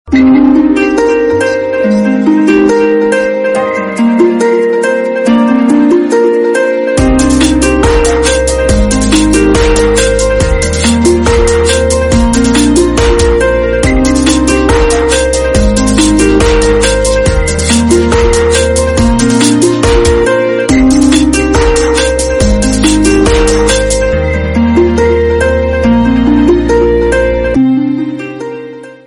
主日講道